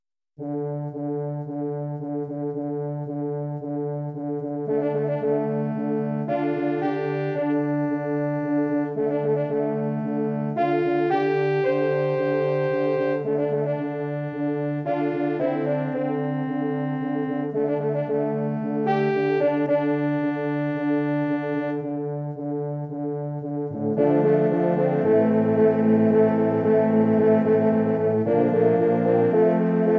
Arrangement / Hornensemble / Hornoktett / TV / Film
Bearbeitung für Hornoktett
Besetzung: 8 Hörner
Arrangement for horn octet
Instrumentation: 8 horns